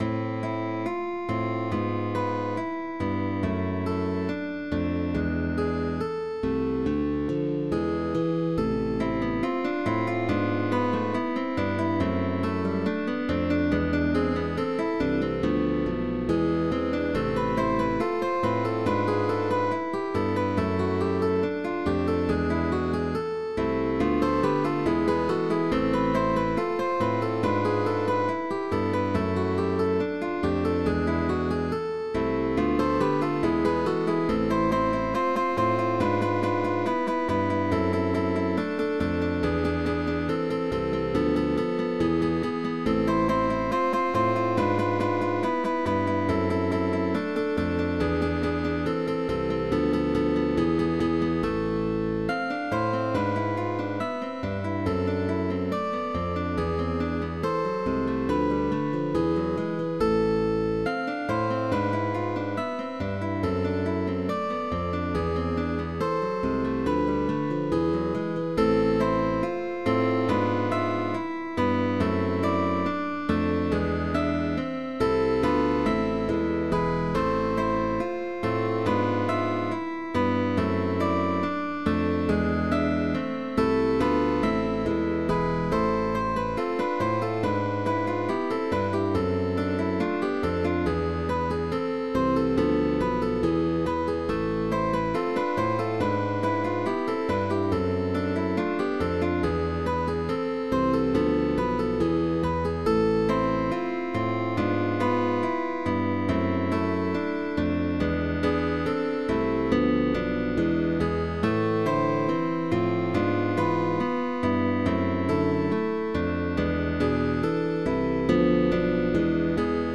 GUITAR QUARTET
first movement (Allegro)